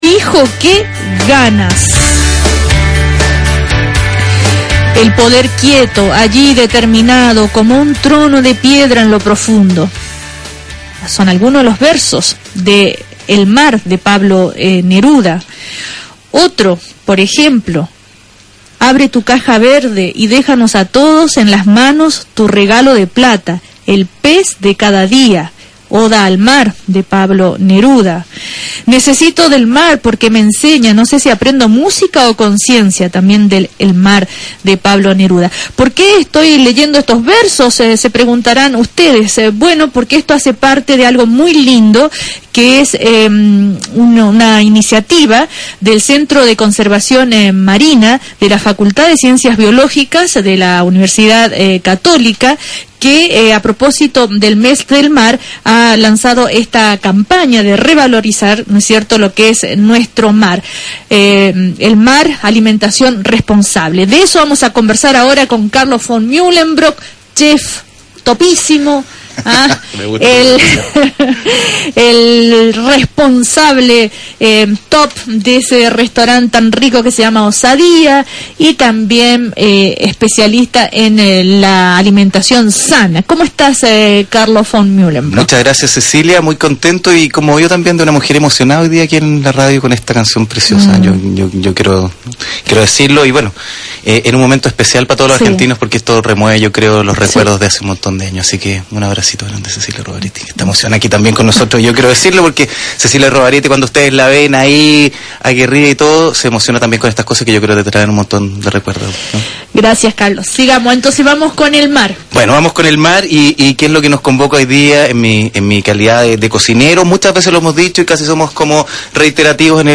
En una entrevista en el programa Una Nueva Mañana, de radio Cooperativa, el chef Carlo Von Mühlenbrock se refirió a la iniciativa Chile es Mar, de nuestro Centro de Conservación Marina, indicándolo como “un proyecto precioso”.
Entrevista-Carlo-Von-Mühlenbrock-MAR-Alimentación-Responsable.mp3